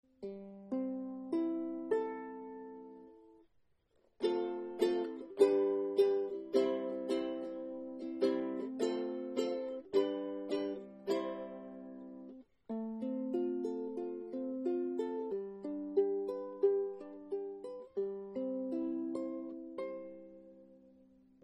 Meine Soundbeispiele sind alle am gleichen Tag mit einem Zoom H2 aufgenommen.
Gespielt habe ich nur ein paar Akkorde.
Ortega Konzert      mit Aquila-Saiten Low-G
Kopus massiv Mahagoni
nachgerüstet mit passivem Untersteg-Tonabnehmer